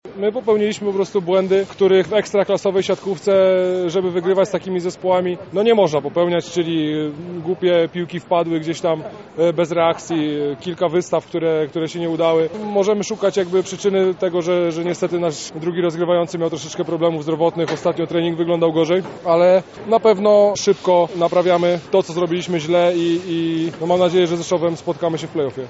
Wywiady